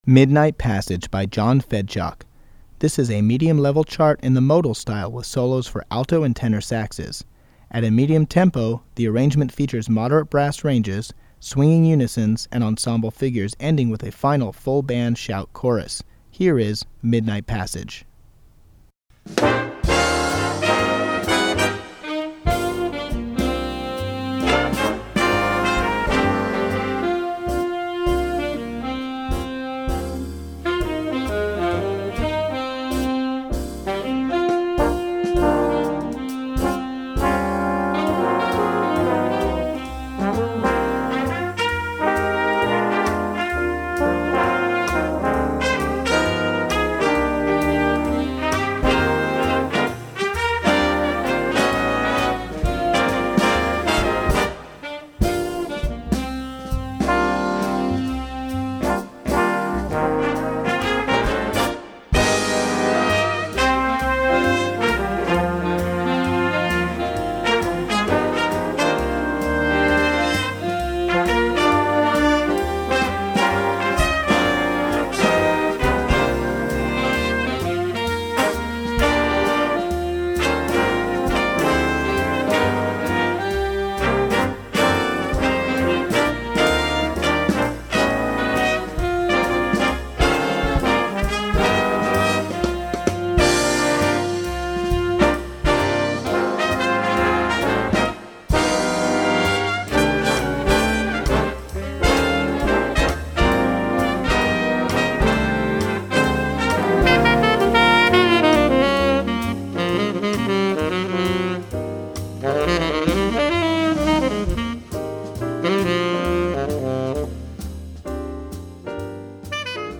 Voicing: Jazz Ensemble